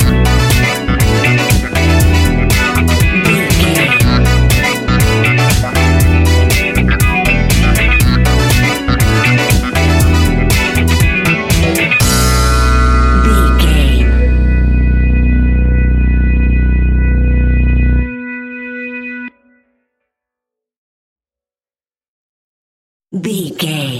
Aeolian/Minor
groovy
futuristic
hypnotic
uplifting
drum machine
synthesiser
funky house
disco house
electronic funk
energetic
upbeat
synth leads
Synth Pads
synth bass